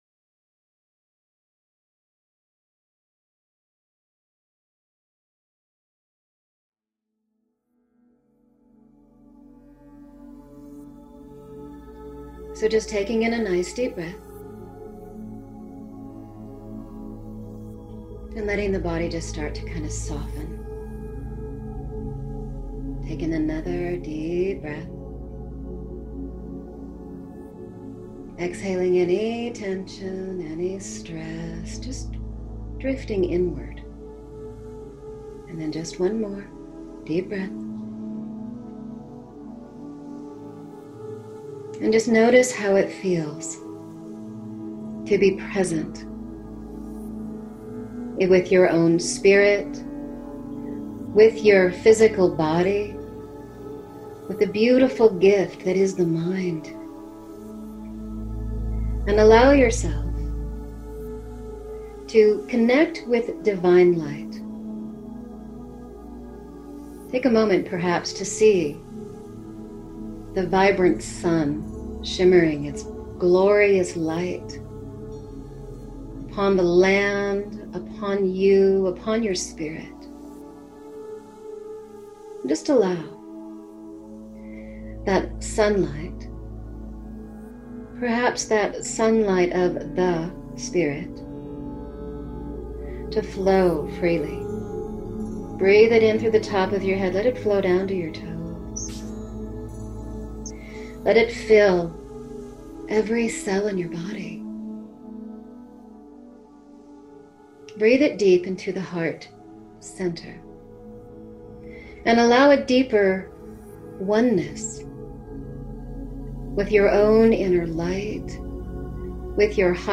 Guided Meditation in a soothing voice. Energy healing, energy clearing, Divine Light, meditation, spirituality.
Allow+Your+Mission+Meditation.mp3